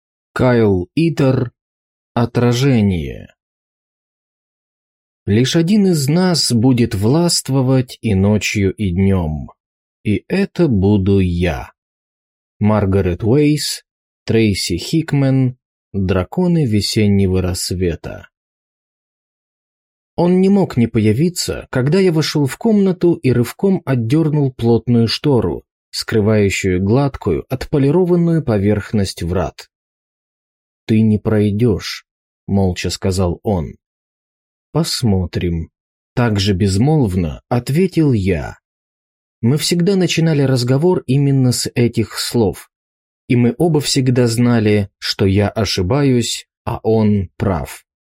Аудиокнига Отражение | Библиотека аудиокниг